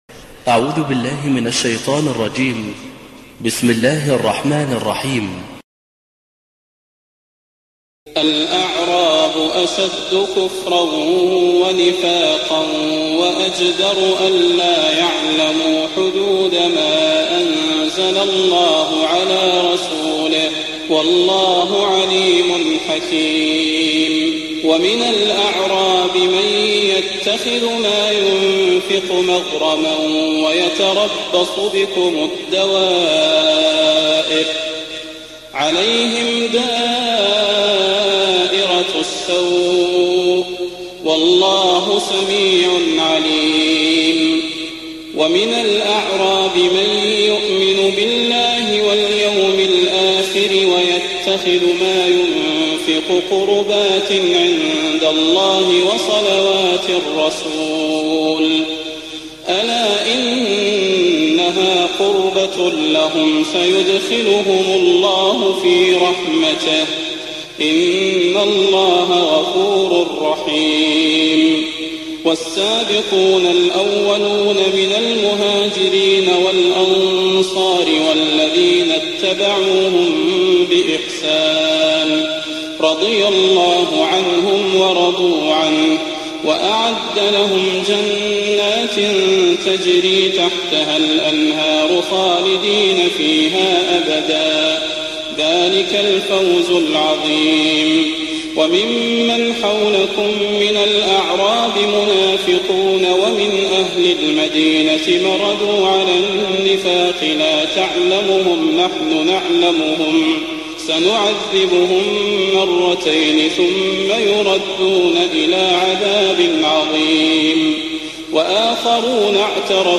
تهجد ليلة 29 رمضان 1419هـ من سورتي التوبة (97-129) يونس (1-70) Tahajjud 29th night Ramadan 1419H Surah At-Tawba and Surah Yunus > تراويح الحرم النبوي عام 1419 🕌 > التراويح - تلاوات الحرمين